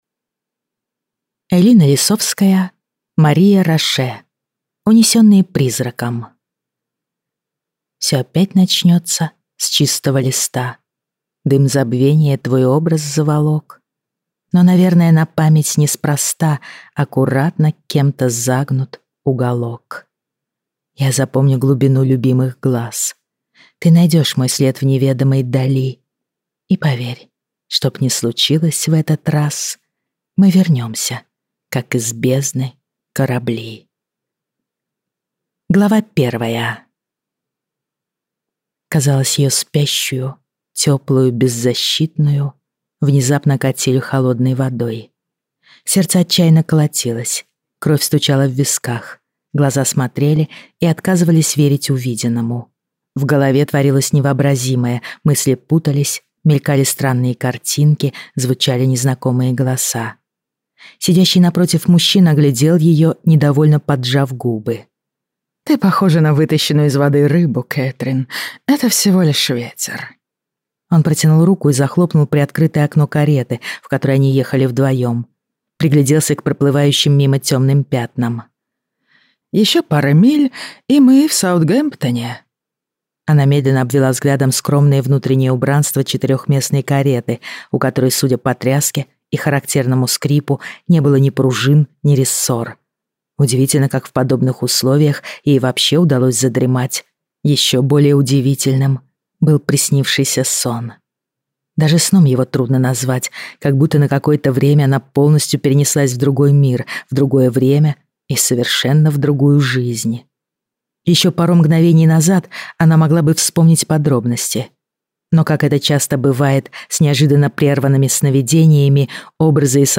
Аудиокнига Унесённые «Призраком» | Библиотека аудиокниг